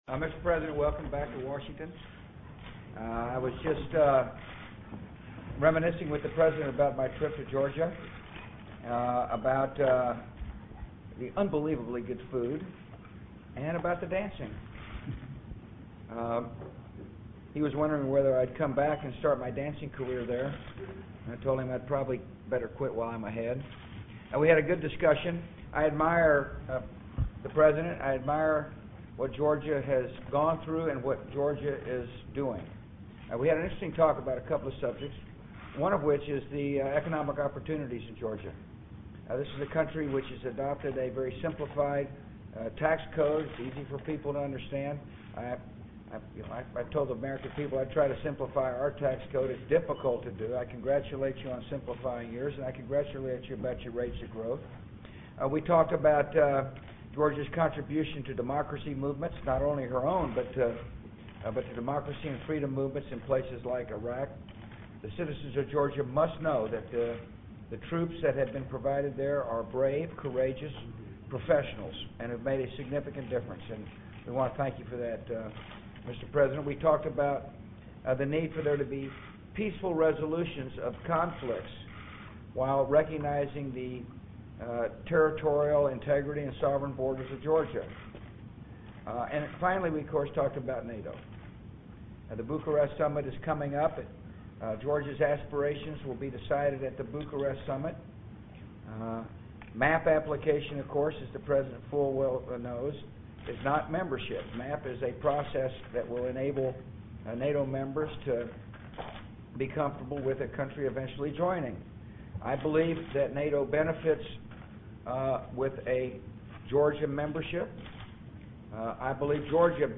布什会见格鲁吉亚总统讲话(2008-03-19) 听力文件下载—在线英语听力室